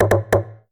notification_sounds
dry bongos.mp3